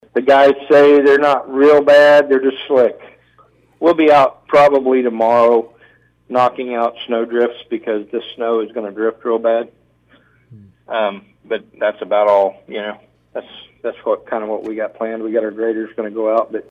After the meeting, Commissioner Troy Friddle talked about the road conditions.